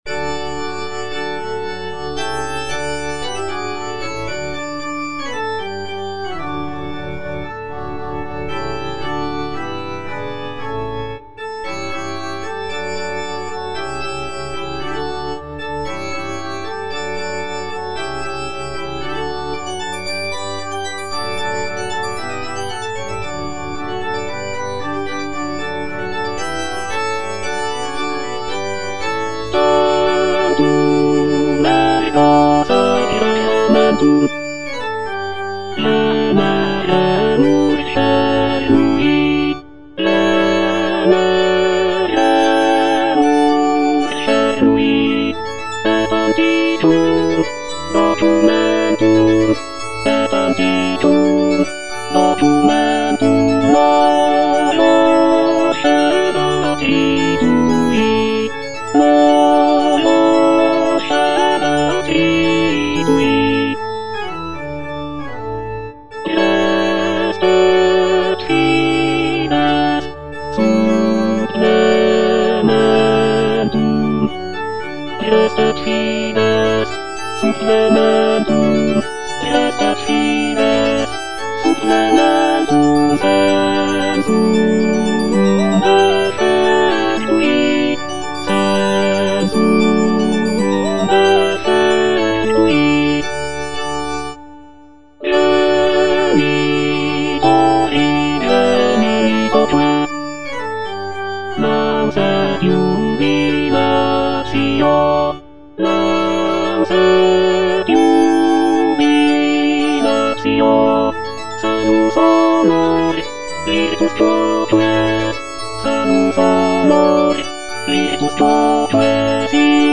W.A. MOZART - TANTUM ERGO KV197 Tenor (Emphasised voice and other voices) Ads stop: auto-stop
"Tantum ergo KV197" is a sacred choral work composed by Wolfgang Amadeus Mozart in 1774.
With its serene and contemplative character, "Tantum ergo KV197" remains a testament to Mozart's exceptional talent in composing religious music.